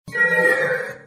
alarm-SGAoffworld.ogg